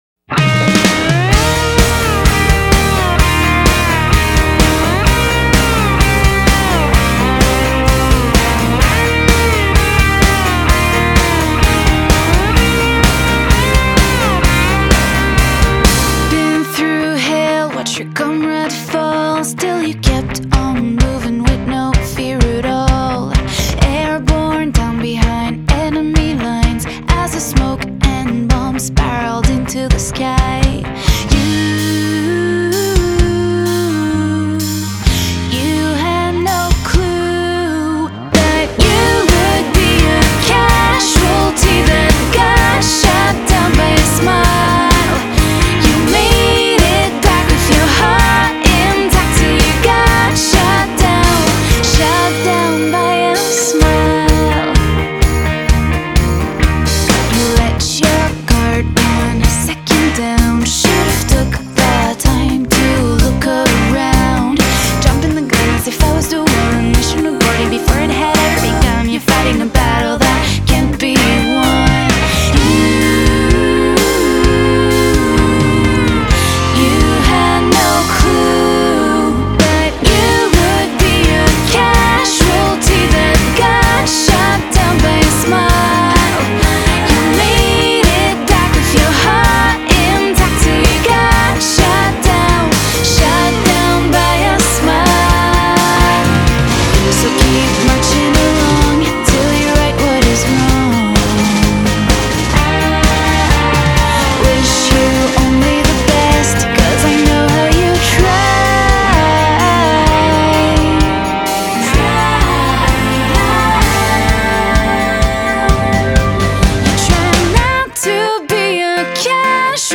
Genre.........................: Pop